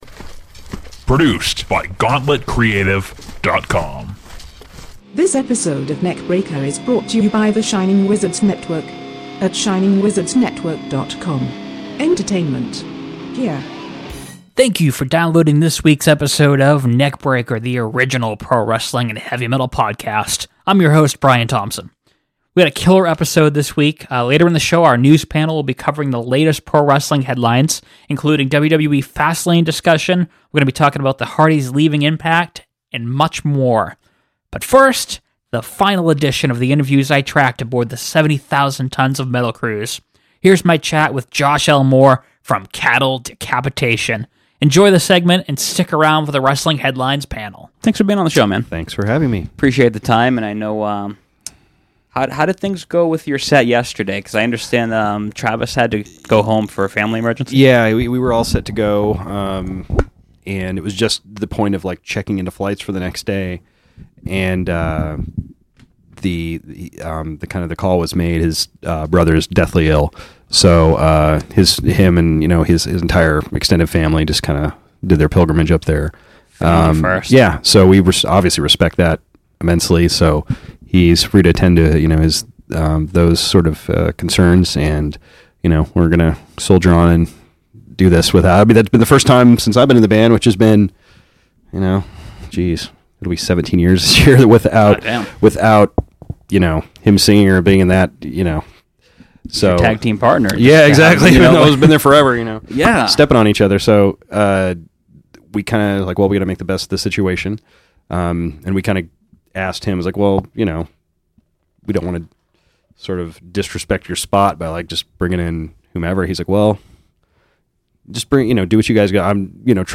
aboard 70000Tons! They discuss basement shows, 80’s wrestlers, the Metal Blade anniversary tour, and more.
Segment breakdowns: 0:00:47 Interview...